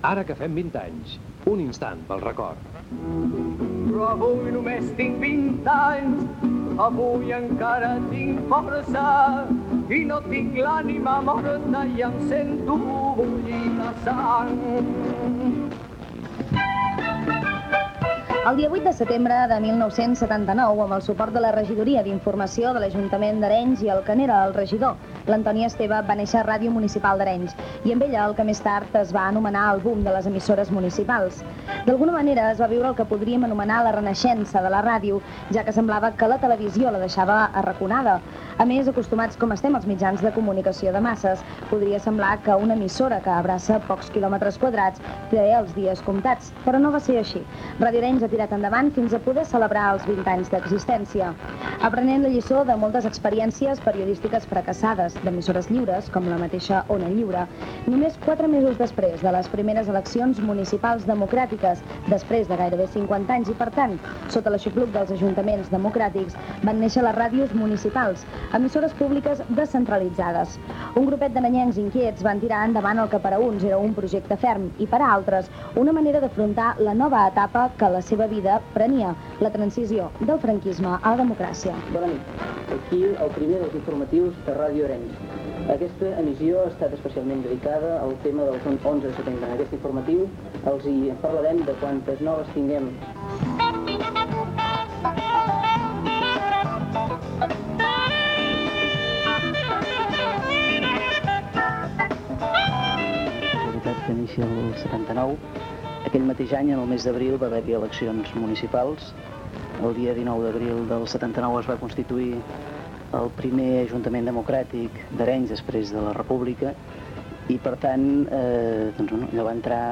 9be563d38b52dcbc2bd470e2bbbf5f4fad4b6794.mp3 Títol Ràdio Arenys Emissora Ràdio Arenys Titularitat Pública municipal Descripció Programació especial amb motiu dels 20 anys de Ràdio Arenys
Gènere radiofònic Informatiu